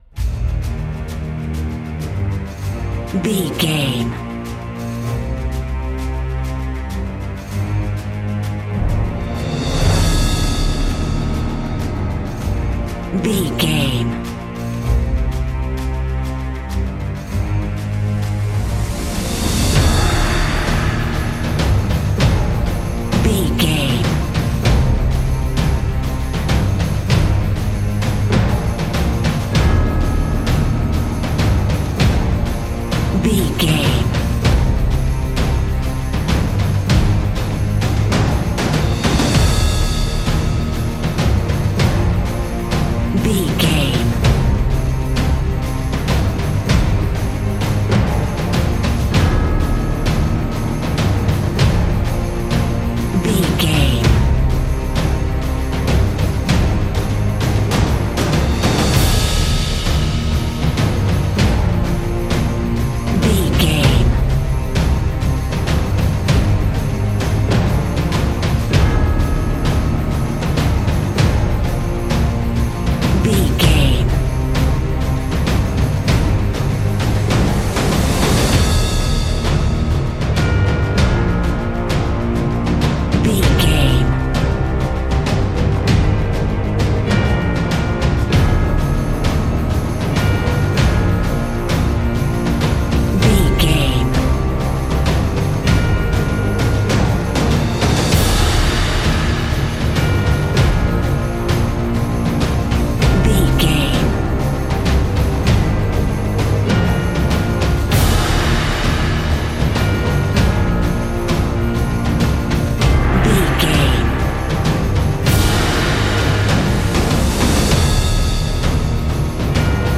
Ionian/Major
E♭
cinematic
energetic
powerful
brass
cello
drums
flute
strings
trumpet